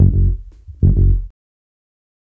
Bass Lick 34-05.wav